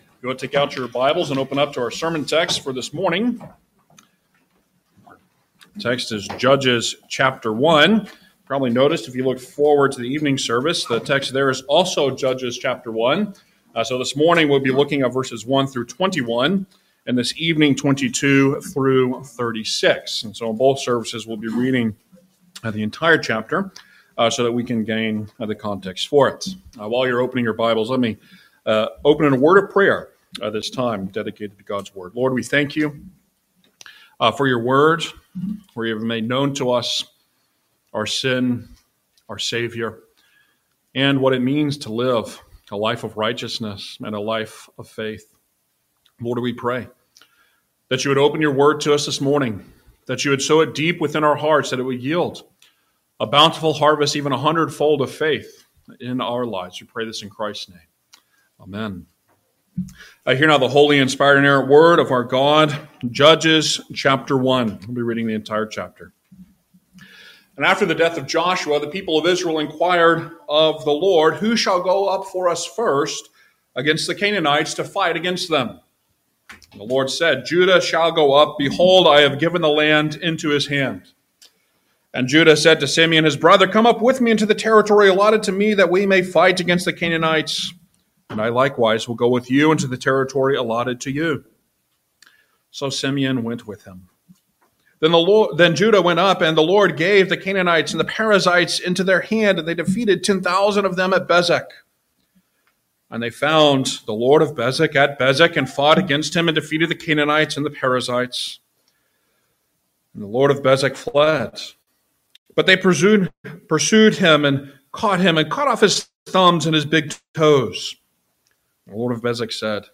Series: Guest Preachers Passage: Judges 1:1-21 Service Type: Sunday Morning Service Download the order of worship here .